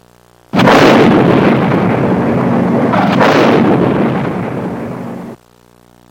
explosion 3